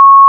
**🔊 SFX PLACEHOLDERS (23 WAV - 1.5MB):**
**⚠  NOTE:** Music/SFX are PLACEHOLDERS (simple tones)
hammer_nail.wav